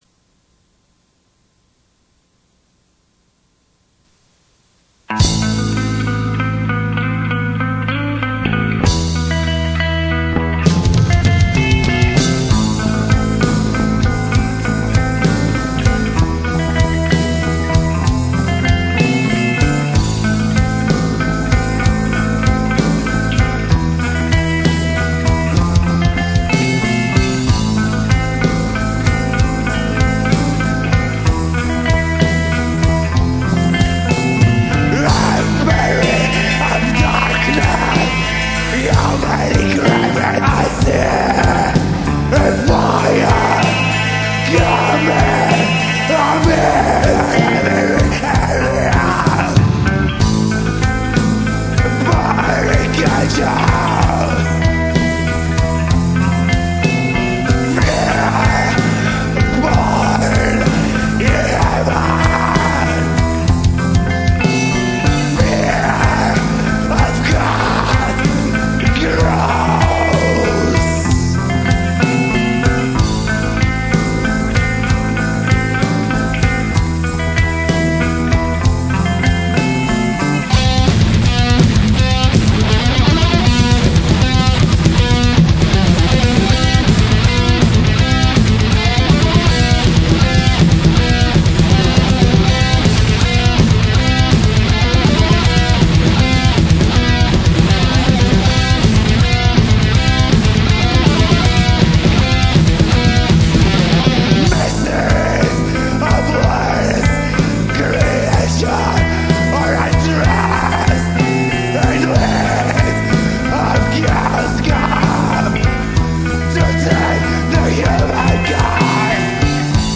vocals, keyboards
guitars
- bass guitars
drums
Female vocals